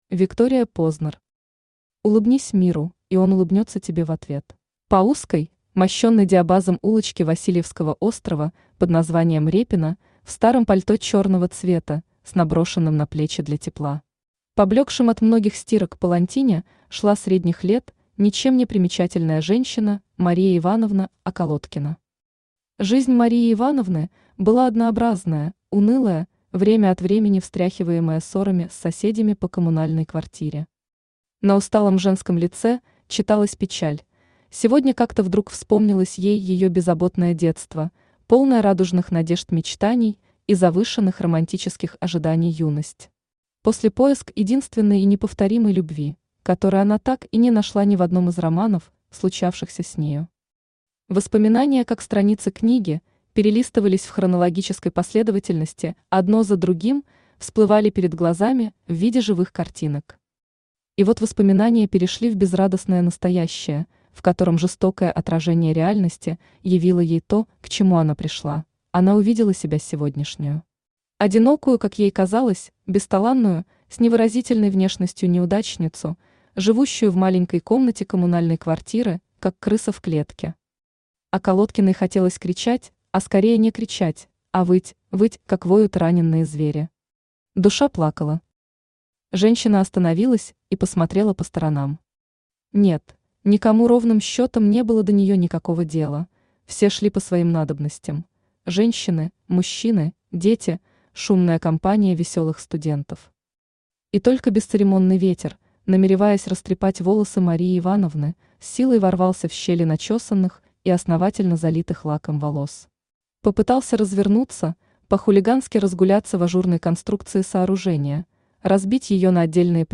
Аудиокнига Улыбнись миру, и он улыбнется тебе в ответ | Библиотека аудиокниг
Aудиокнига Улыбнись миру, и он улыбнется тебе в ответ Автор Виктория Познер Читает аудиокнигу Авточтец ЛитРес.